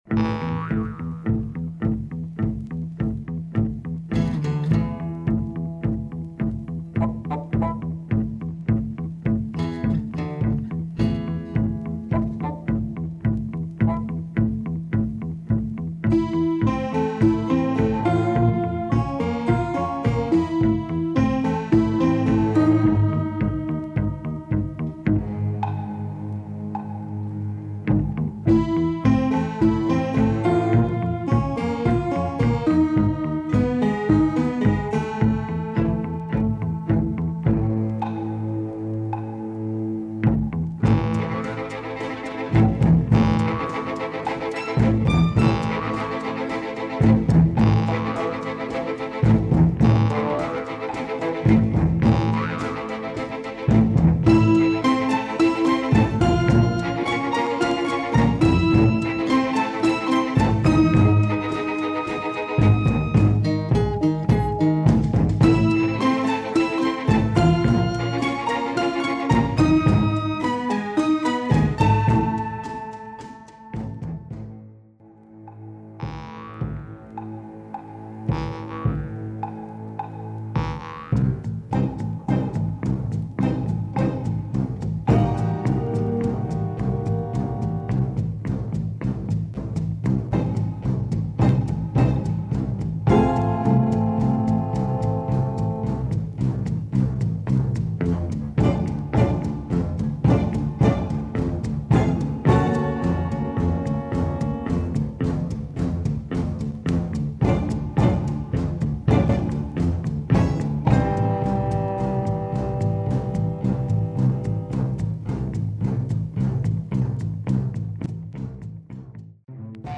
Some Madlib-esque beats here !